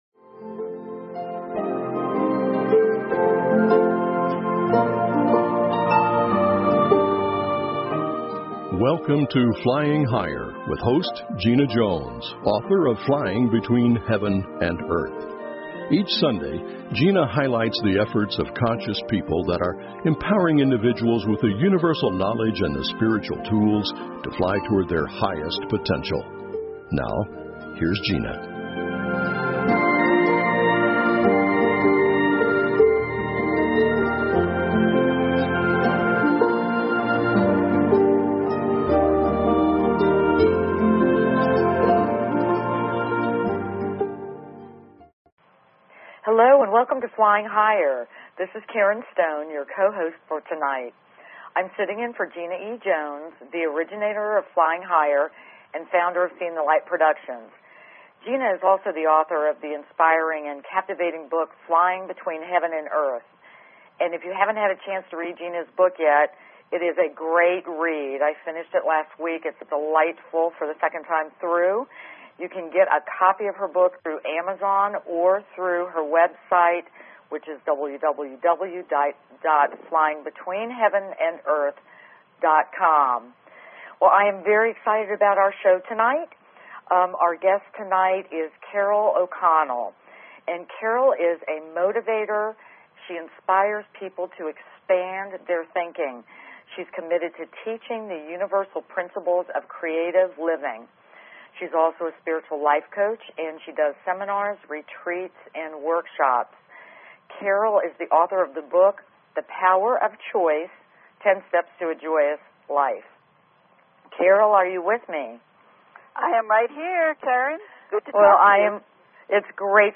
Talk Show Episode, Audio Podcast, Flying_Higher and Courtesy of BBS Radio on , show guests , about , categorized as